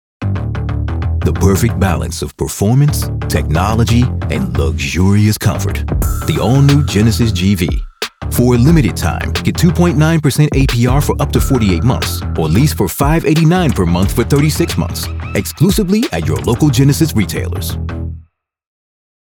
Zuversichtlich
Befehlend
Kenntnisreich